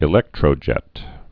(ĭ-lĕktrō-jĕt)